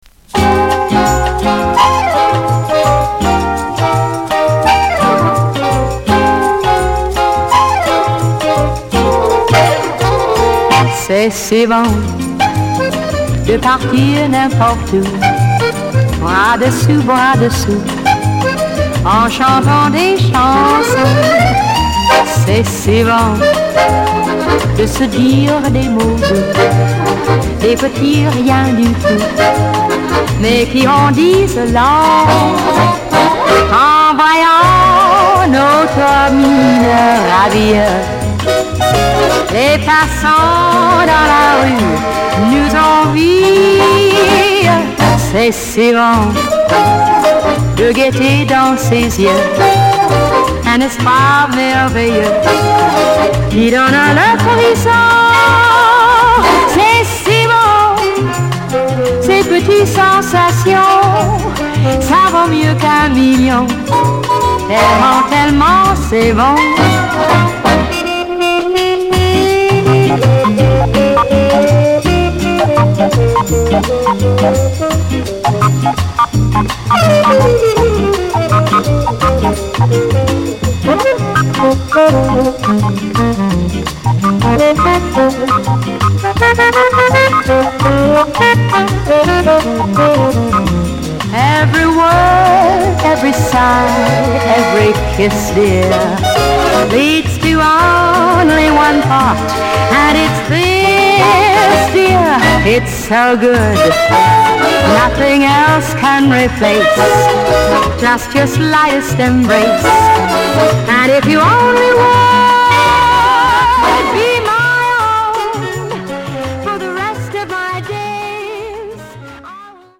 Bossa / Samba us
心地よく跳ねるボッサ・ビート、華やかなアコーディオンを背にしっとり歌い上げた好カヴァーです。